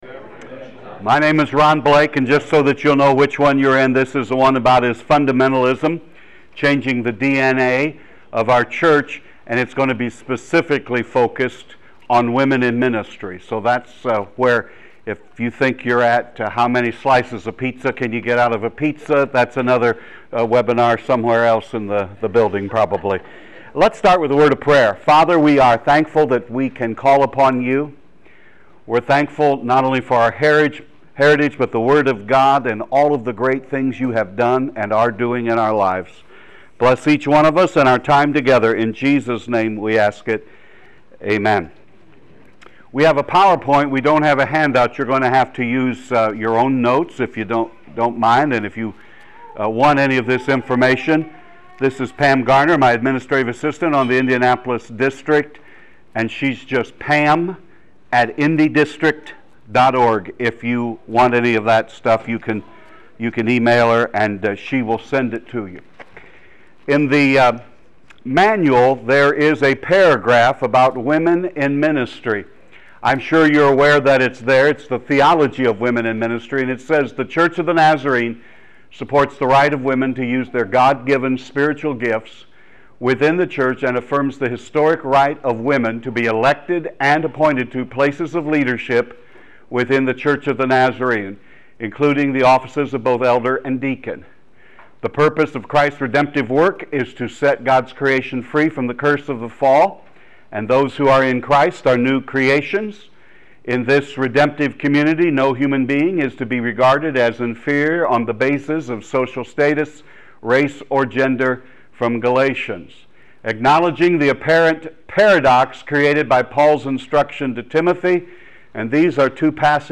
How do we maintain Scriptural, historical, and denominational integrity in regard to creating a welcoming atmosphere for women in ministry? This workshop looks at the Bible record concerning women in ministry with special attention to biblical texts often used by fundamentalists to exclude women from ministry.